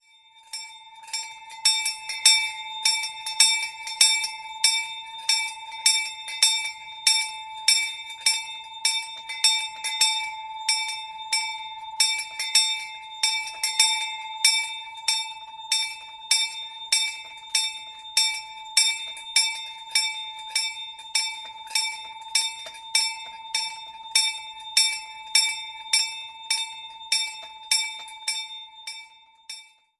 Die Glocke der Waldkapelle Wildenstein